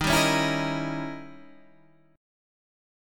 D# 7th Flat 9th